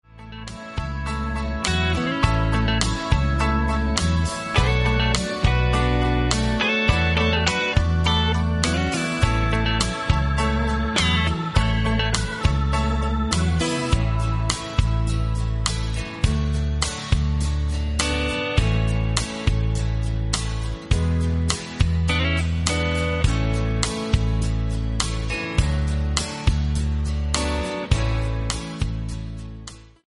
MP3 – Original Key – Backing Vocals Like Original